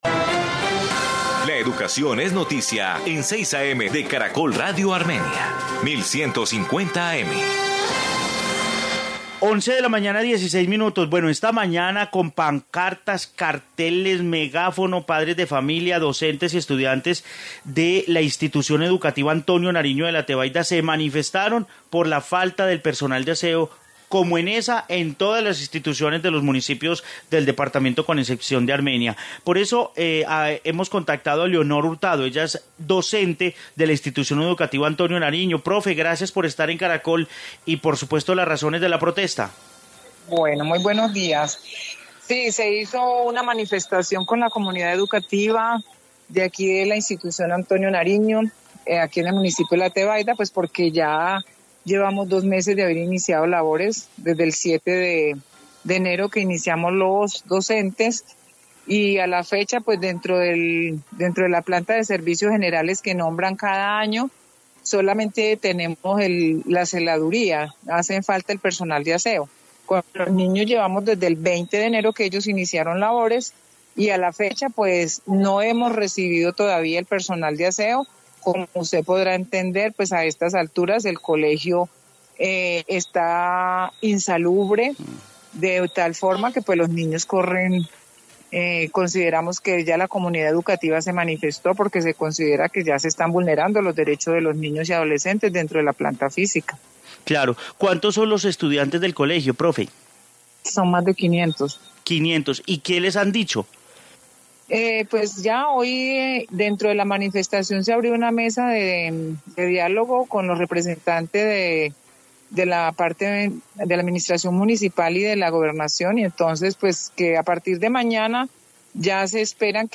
Informe aseo colegios del Quindío